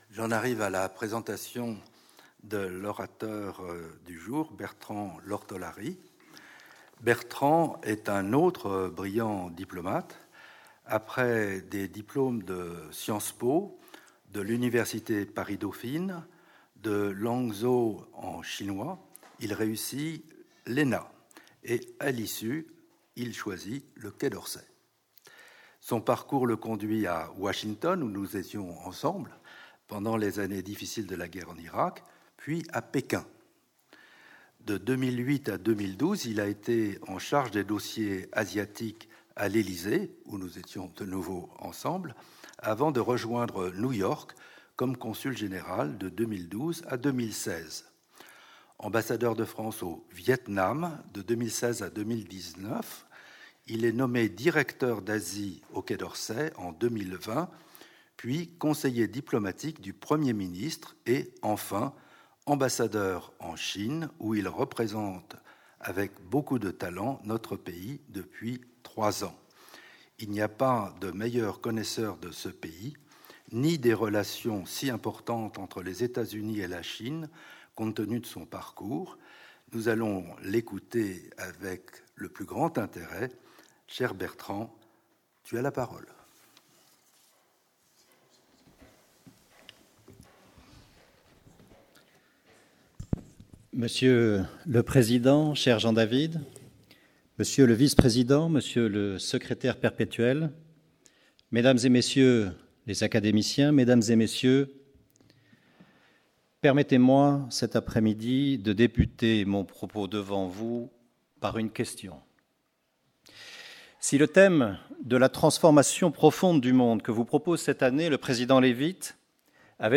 L’ambassadeur de France en Chine propose une mise en perspective de la transformation du monde à travers l’ascension spectaculaire de la Chine.